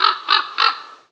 Звуки птиц. Sounds of birds.
Звук прерывистое карканье вороны.